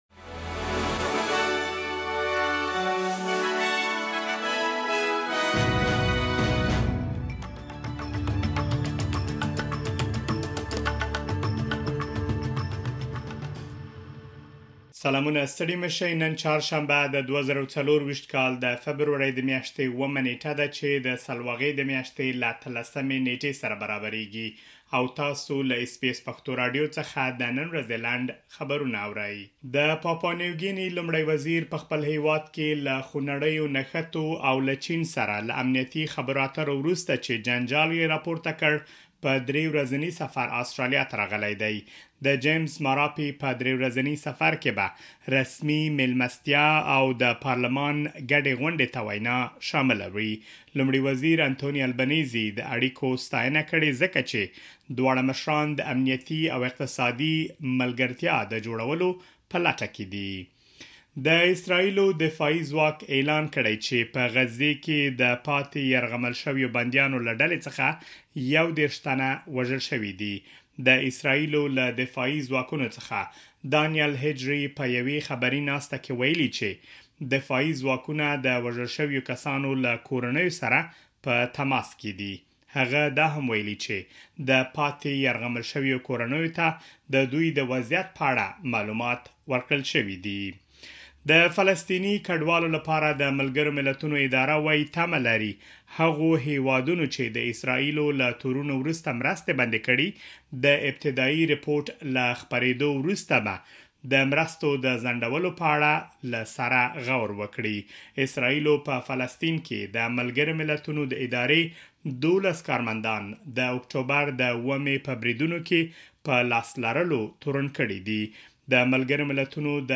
د اس بي اس پښتو راډیو د نن ورځې لنډ خبرونه |۷ فبروري ۲۰۲۴